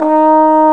Index of /90_sSampleCDs/Roland L-CDX-03 Disk 2/BRS_Trombone/BRS_Tenor Bone 3